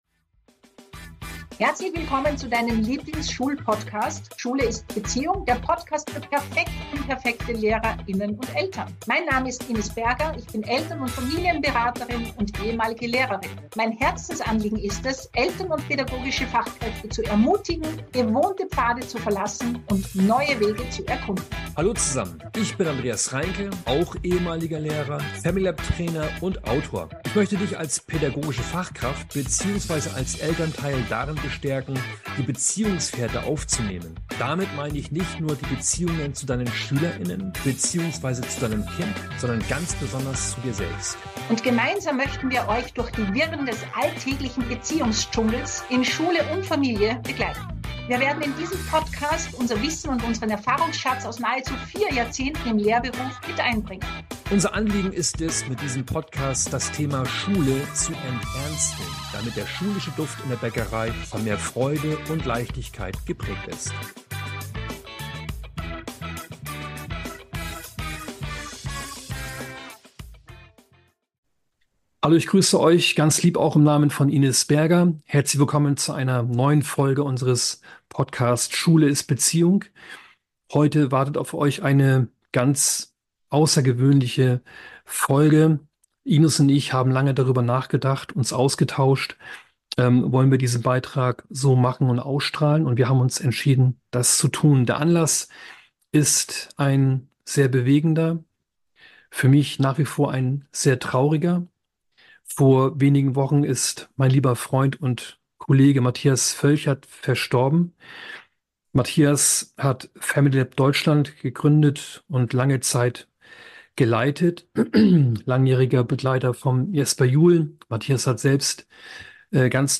Nach einigen einführenden Worten hörst du in der neuen Folge unseres Podcast "Schule ist Beziehung" das Interview